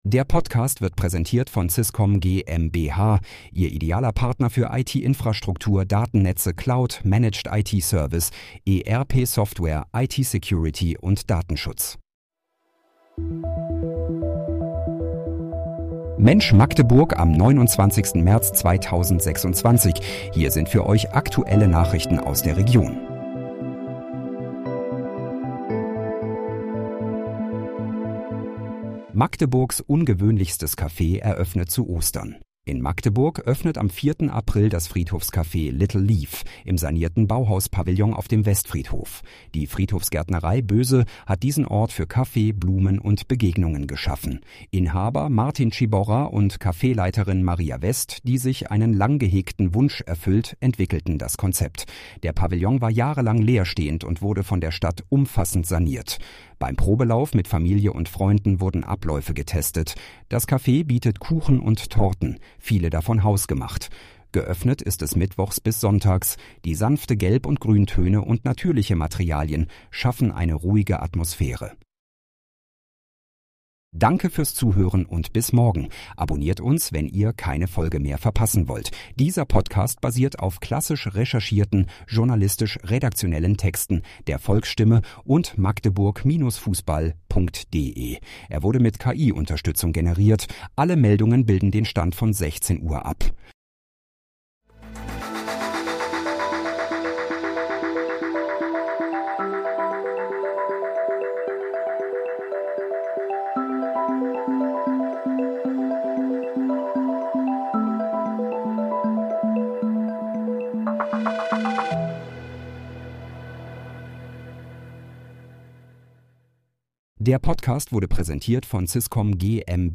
Mensch, Magdeburg: Aktuelle Nachrichten vom 29.03.2026, erstellt mit KI-Unterstützung
Nachrichten